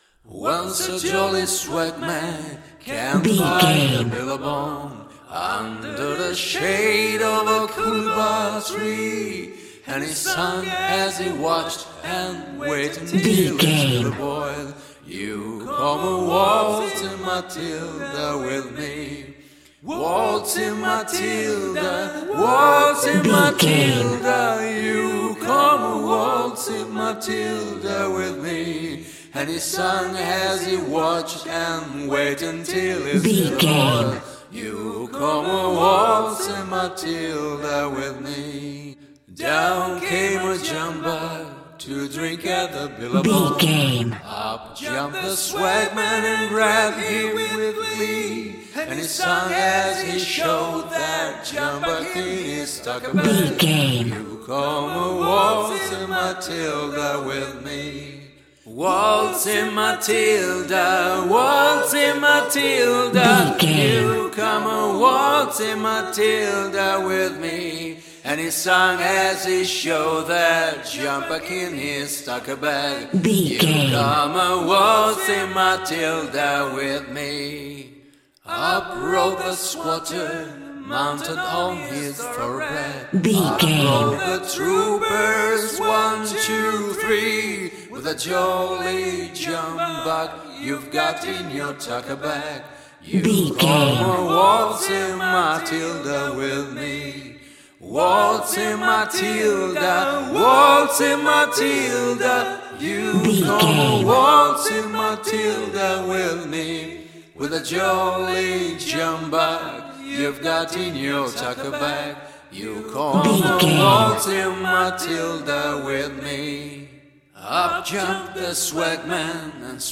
Aeolian/Minor
G♭
groovy